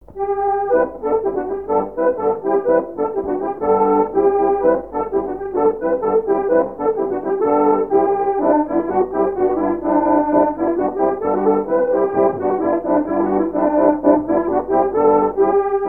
Mémoires et Patrimoines vivants - RaddO est une base de données d'archives iconographiques et sonores.
branle
Divertissements d'adultes - Couplets à danser
Pièce musicale inédite